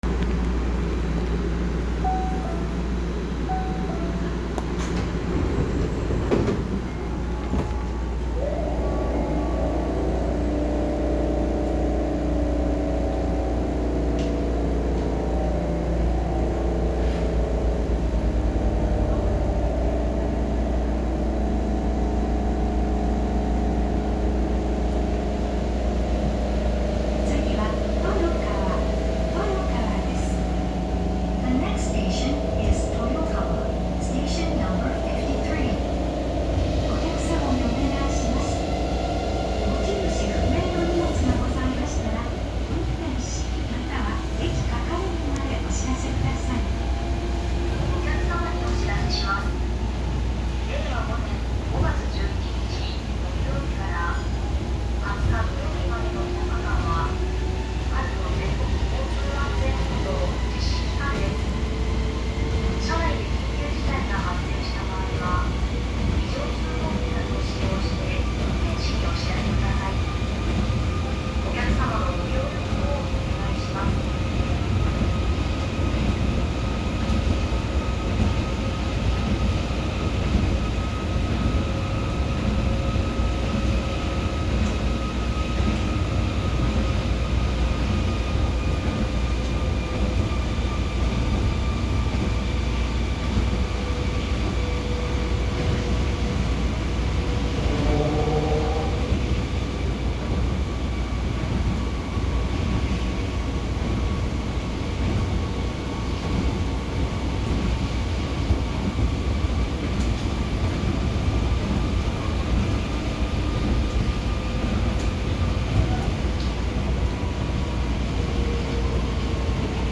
・1000系走行音
【彩都線】阪大病院前〜豊川…1123Fにて
界磁チョッパ式の走行装置で、モーター音は大変大人しく、目立ちません。停車中には700系以降の新幹線で聞くことのできるチャイムが流れ、ドアチャイムは阪急と同じ。そして車内アナウンスの声は東京モノレールと同じです。